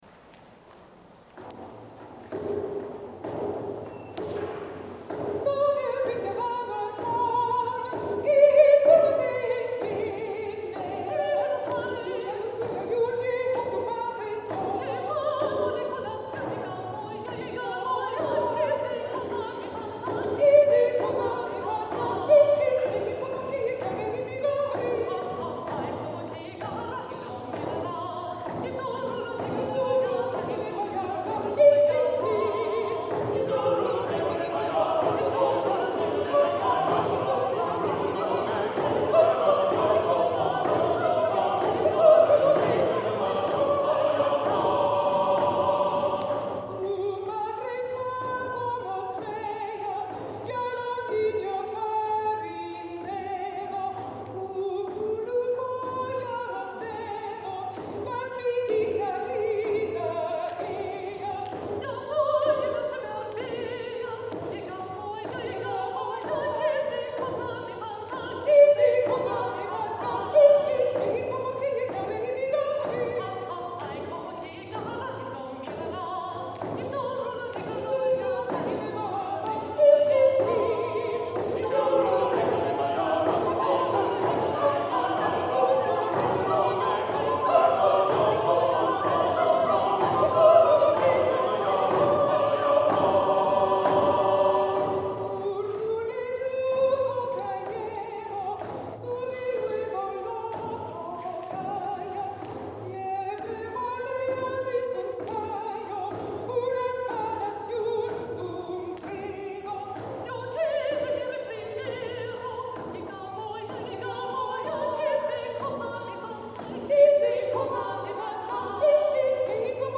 Many of the pieces are lively spiritual villancicos written for Christmas and Corpus Christi, which were especially requested by the authorities and much appreciated by the populace.